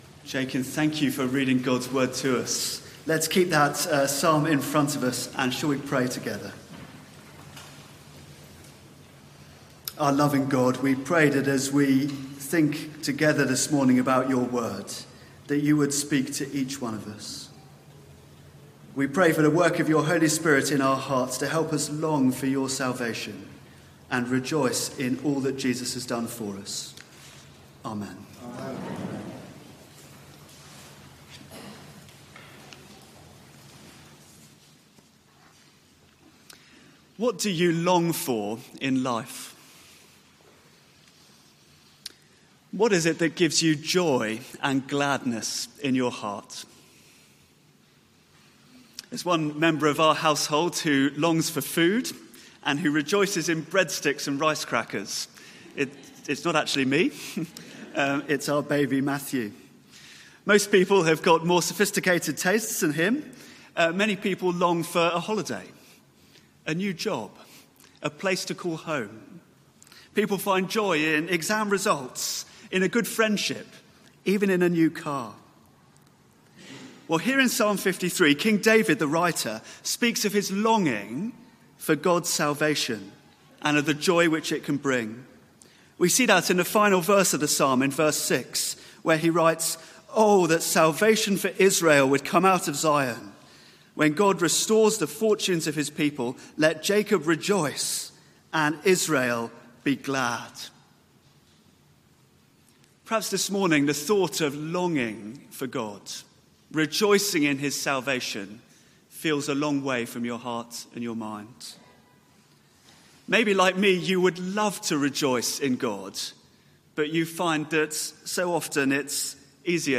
Media for 9:15am Service on Sun 26th Aug 2018 09:15 Speaker
Passage: Psalm 53 Series: Summer Psalms Theme: Sermon Search the media library There are recordings here going back several years.